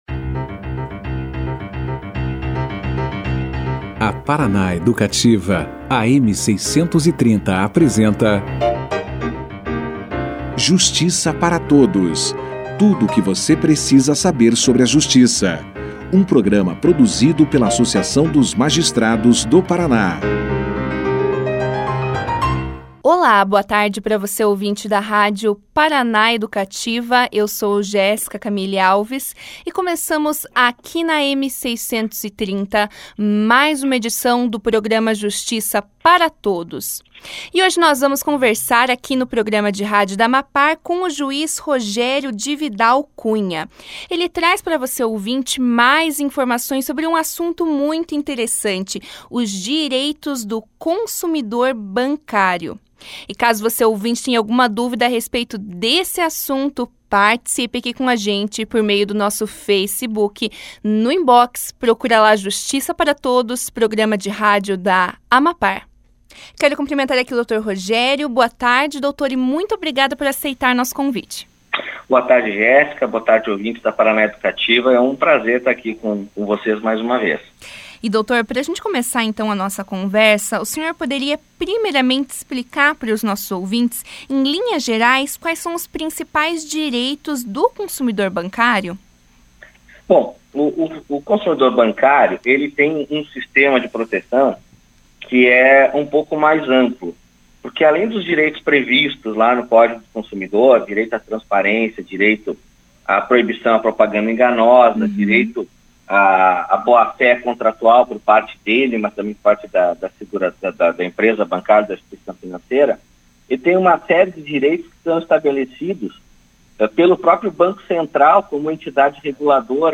Os direitos do consumidor bancário foram discutidos no Justiça para Todos, programa de rádio da AMAPAR, na segunda-feira (1). O juiz Rogério de Vidal Cunha trouxe aos ouvintes da rádio Paraná Educativa mais esclarecimentos sobre a legislação que trata do assunto e também a respeito dos direitos do consumidor mais violados pelas instituições bancárias, dos quais ele destacou à inscrição indevida em cadastros de proteção ao crédito, taxas de juros extremamente elevadas e não encerramento de conta corrente.